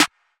snare 12.wav